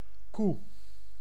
Ääntäminen
Ääntäminen : IPA: /ku/ Haettu sana löytyi näillä lähdekielillä: hollanti Käännös 1. кра́ва {f} Suku: f .